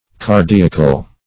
cardiacal - definition of cardiacal - synonyms, pronunciation, spelling from Free Dictionary Search Result for " cardiacal" : The Collaborative International Dictionary of English v.0.48: Cardiacal \Car*di"a*cal\, a. Cardiac.